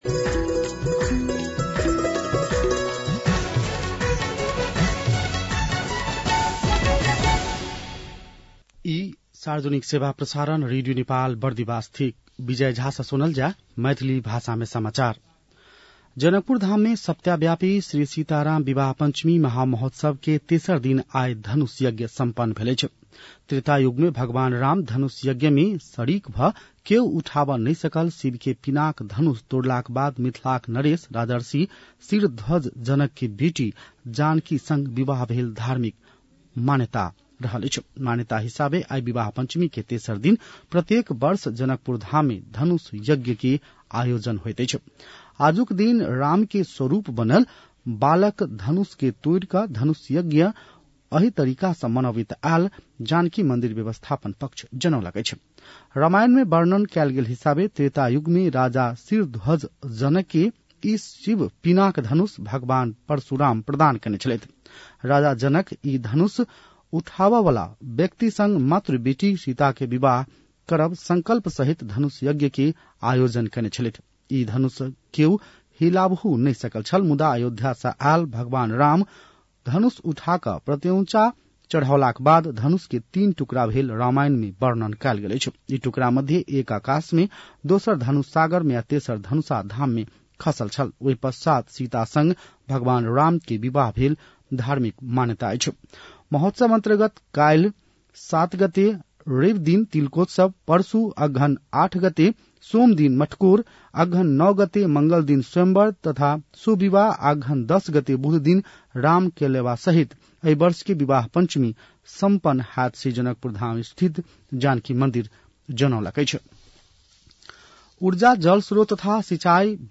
मैथिली भाषामा समाचार : ६ मंसिर , २०८२